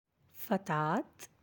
(fatah or fatat)
fatat.aac